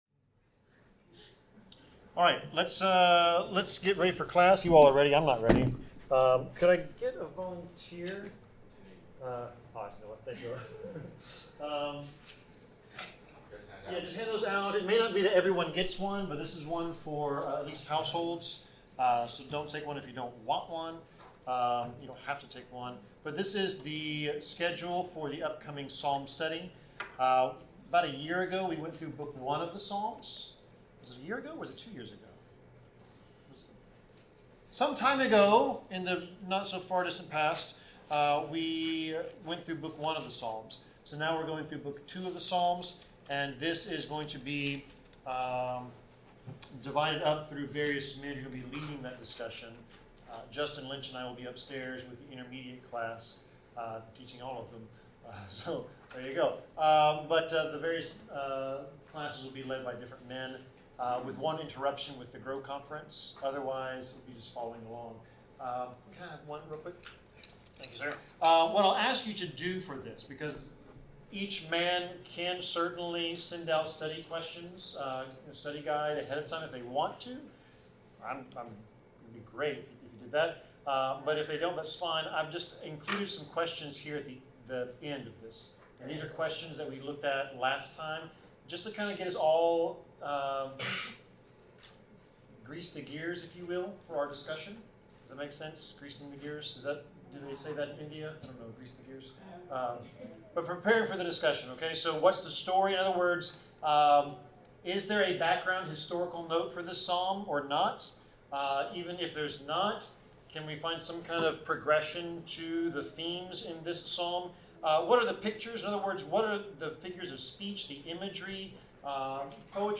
Bible class: Esther 9-10
Service Type: Bible Class